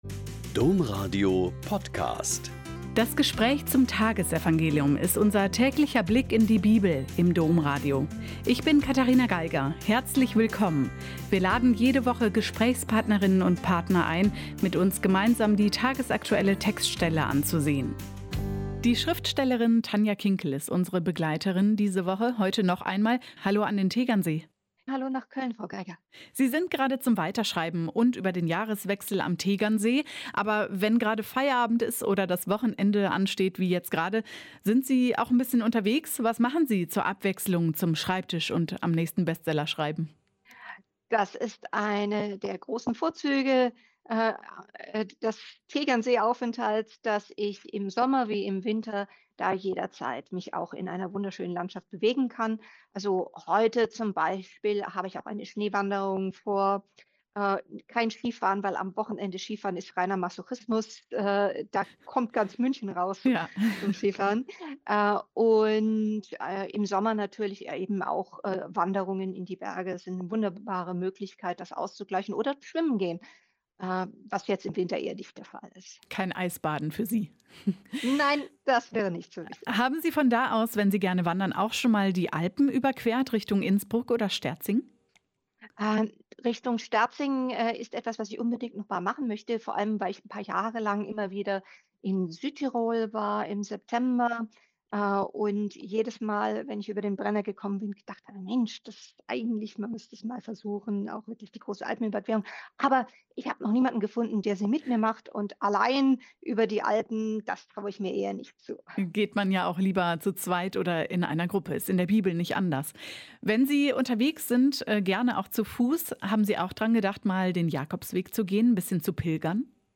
Joh 1,35-42 - Gespräch mit Tanja Kinkel